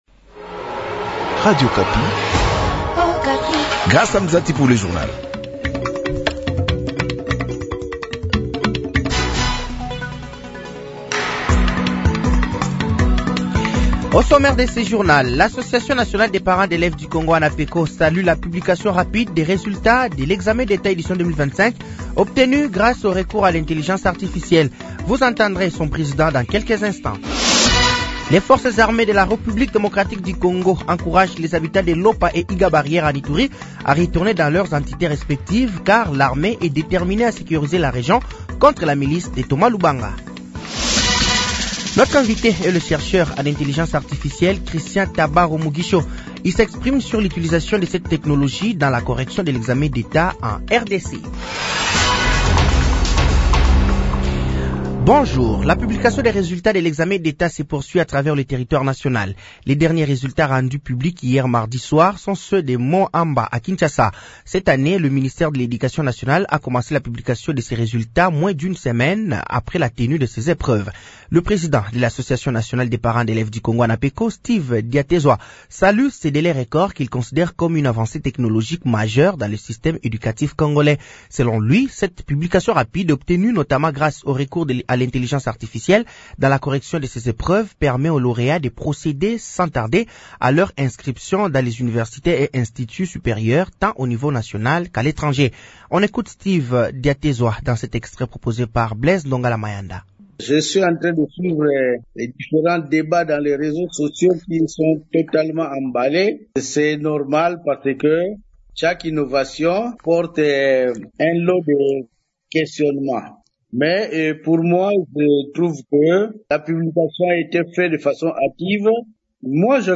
Journal français de 8h de ce mercredi 06 août 2025